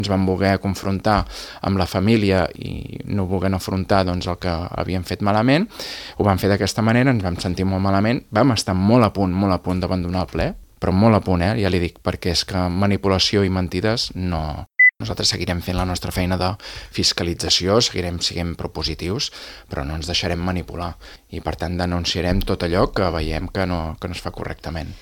Ho ha afirmat el portaveu republicà i cap de l’oposició, Xavier Ponsdomènech, a l’Entrevista Política de Ràdio Calella TV.